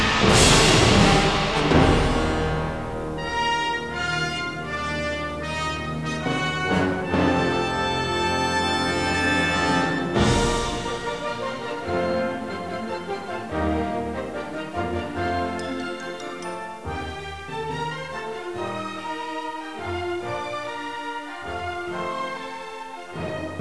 5) THE MUSIC